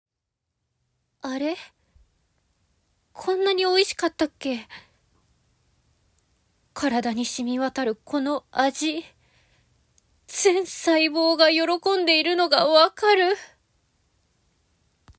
ボイスサンプル
セリフ@